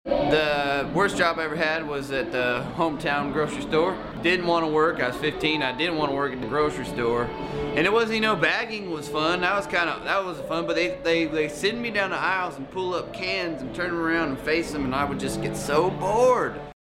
Audio / Jon Pardi talks about his worst job, which was at a grocery store.